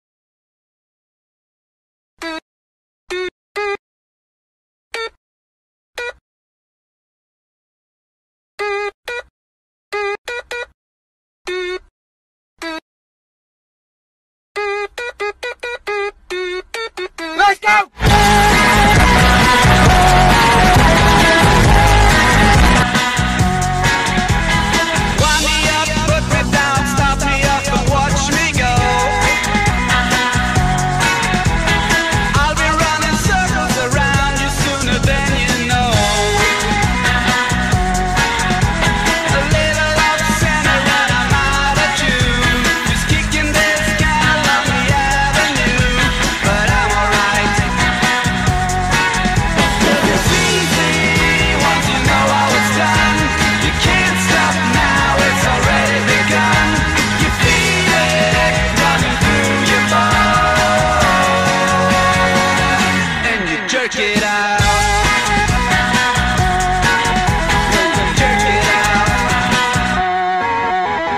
BPM134-266
Audio QualityPerfect (High Quality)